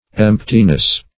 Emptiness \Emp"ti*ness\, n. [From Empty.]